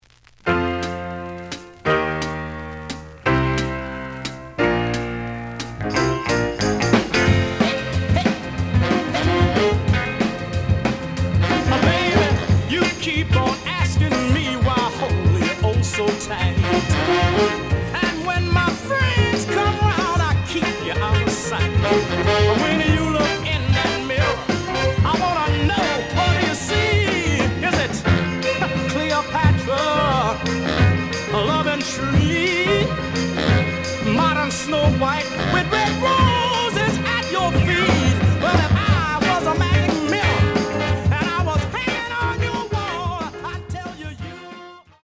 試聴は実際のレコードから録音しています。
The audio sample is recorded from the actual item.
●Genre: Soul, 60's Soul